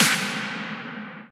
clap-deep.ogg